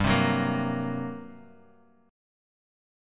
GmM7bb5 Chord